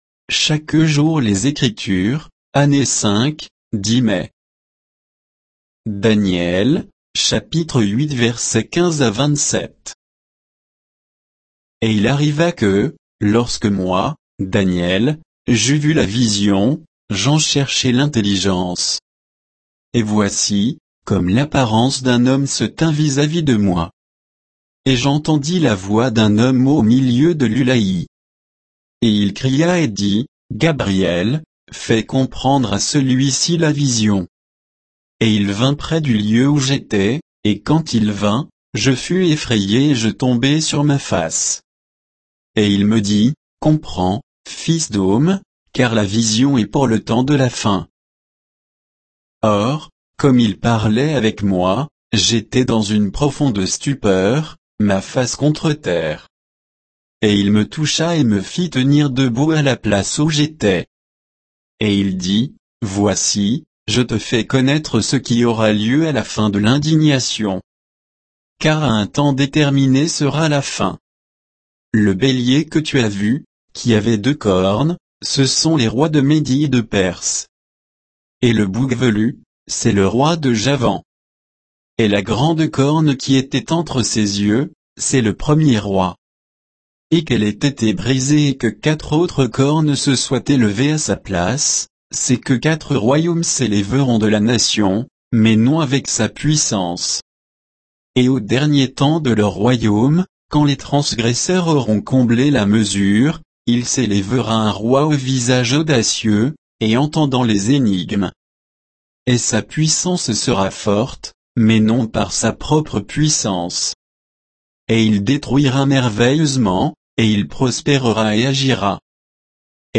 Méditation quoditienne de Chaque jour les Écritures sur Daniel 8